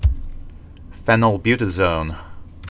(fĕnəl-bytə-zōn, fēnəl-)